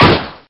damage100_1.ogg